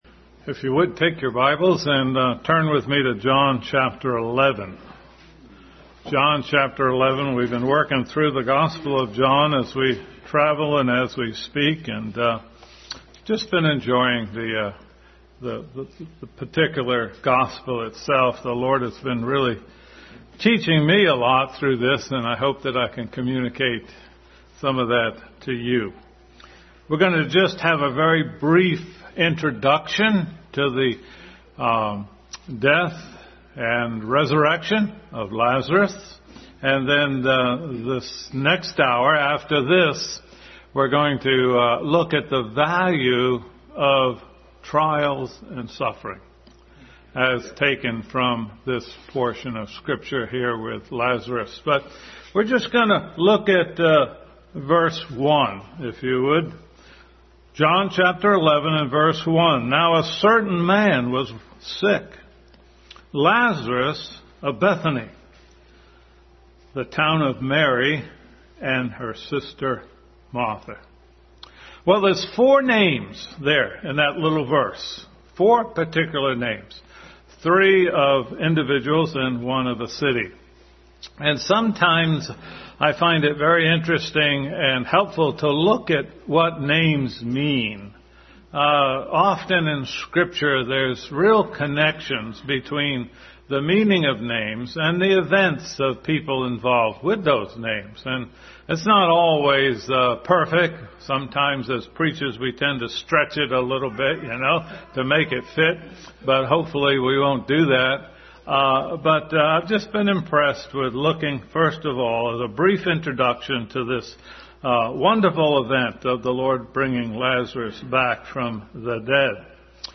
Passage: John 11:1-5, 32-33, 12:3, 11:11, 14, Matthew 26:6, Luke 10:39 Service Type: Sunday School